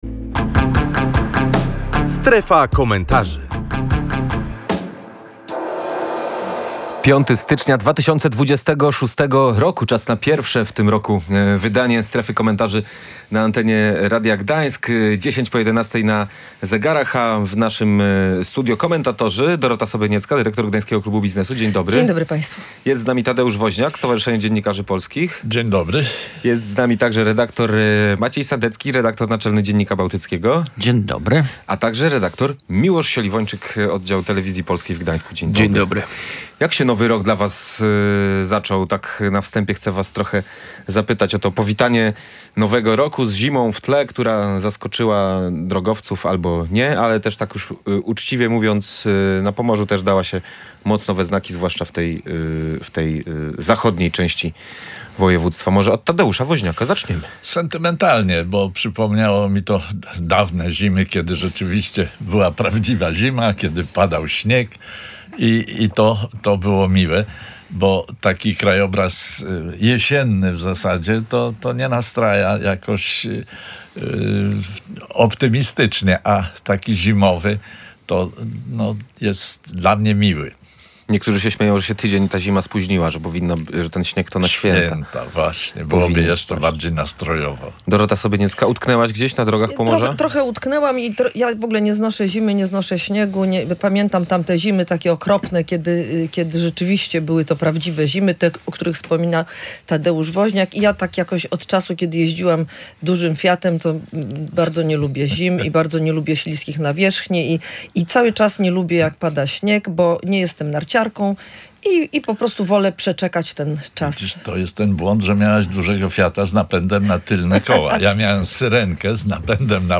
O nowym roku, pogodzie i śnieżnej zimie, która zaskoczyła region, w audycji „Strefa Komentarzy” rozmawiali: